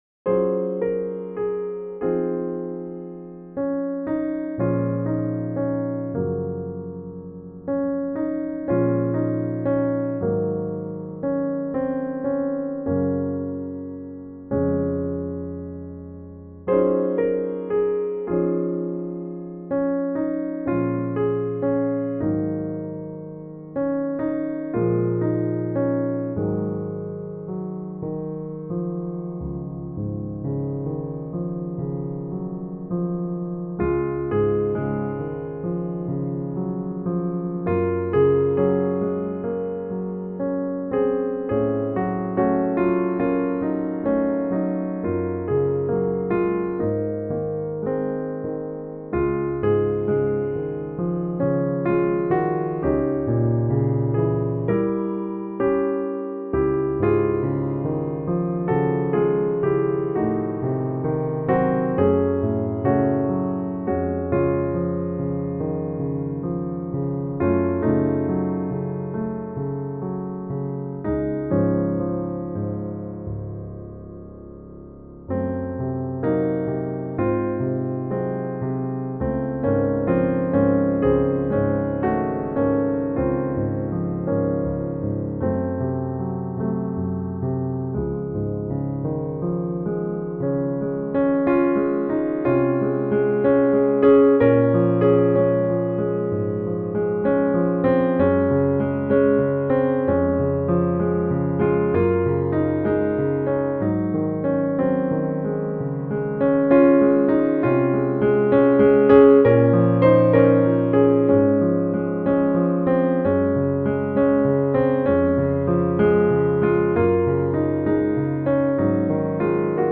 Solo piano piece.